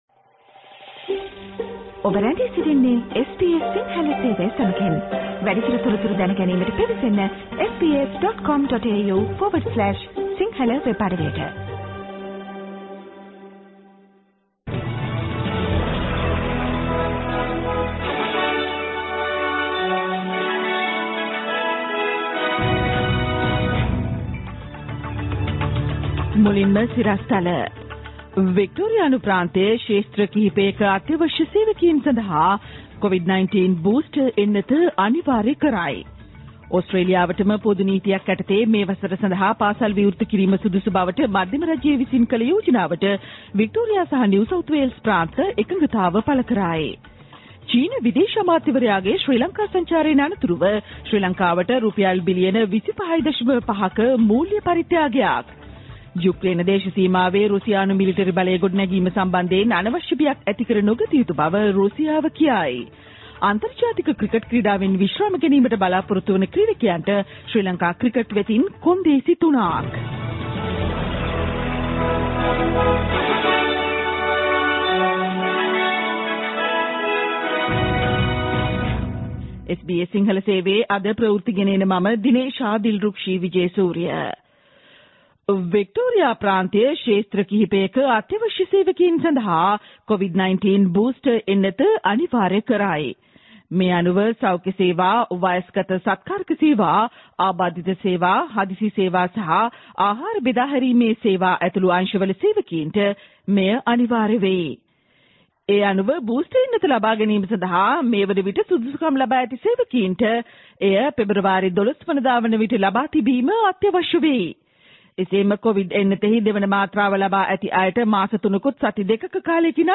Australian news in Sinhala 11 Jan 2022: State of Victoria makes COVID Booster vaccine mandatory for essential workers in several fields